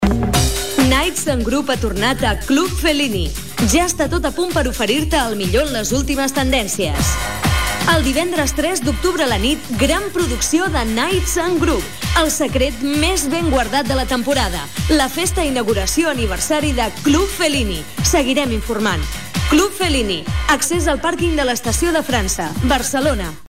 Anunci del Club Fellini Gènere radiofònic Publicitat